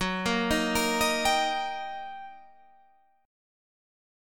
Eb/Gb chord